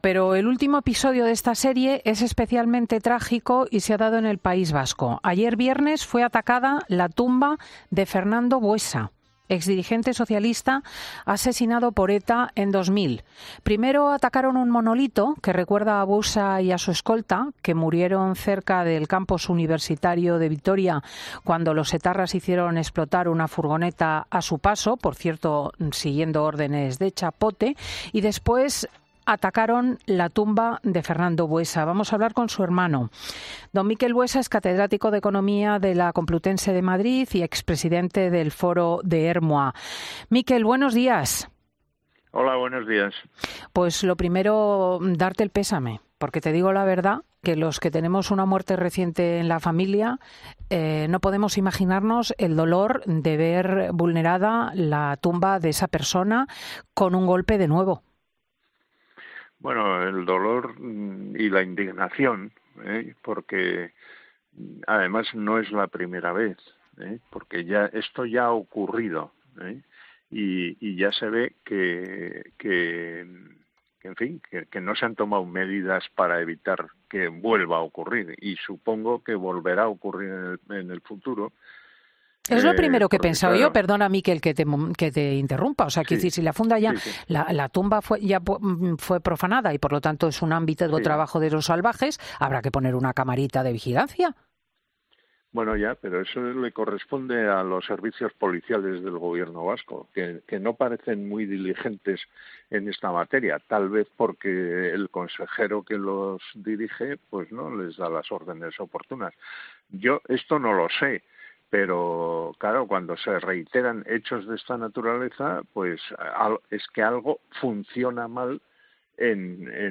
El hermano de Fernando Buesa, asesinado por ETA, hablaba en 'Fin de Semana' de la última profanación a la tumba de su hermano